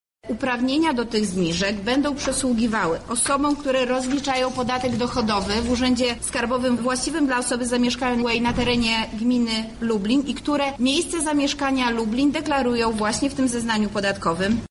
Wszyscy muszą spełniać jednak jeden warunek – mówi Beata Stepaniuk-Kuśmierzak, Zastępca Prezydenta Lublina ds. Kultury, Sportu i Partycypacji: